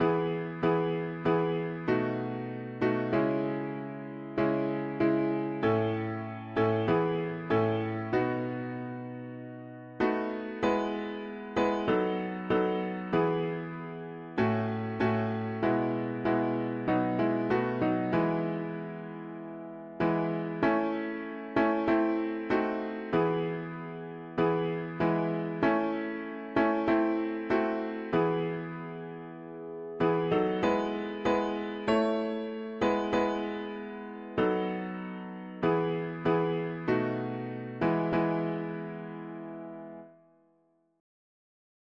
Lyrics: The sands of time are sinking, the dawn of heaven breaks. the summer morn I’ve sighed for, the fair sweet morn awakes; dark, dark hath been the midnig… english christian 4part chords
Key: E major